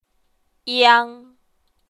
舒声韵的示范发音为阴平调，入声韵则为阴入调。
iang.mp3